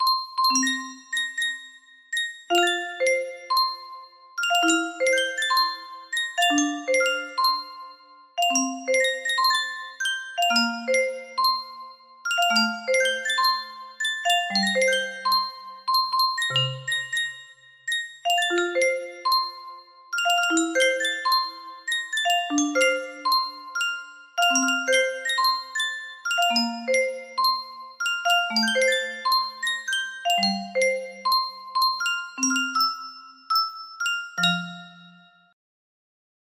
All Because Of You music box melody
Full range 60